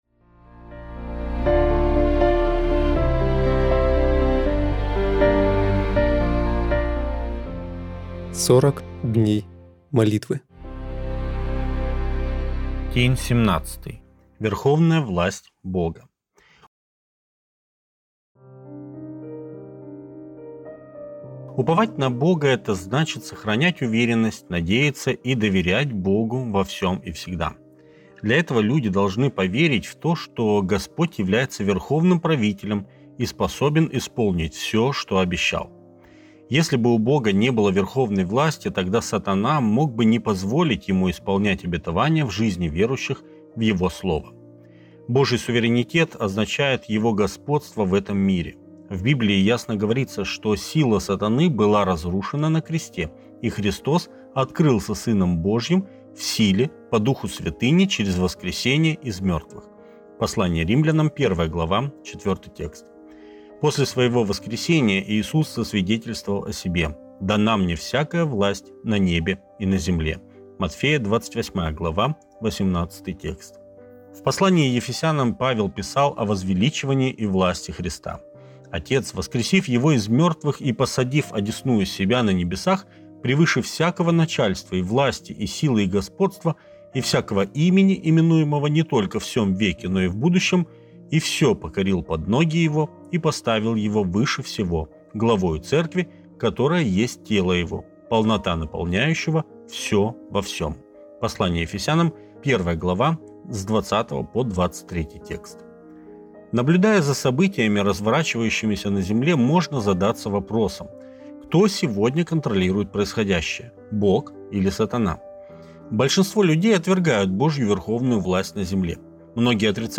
Аудиокнига "40 дней молитвы" - 2 часть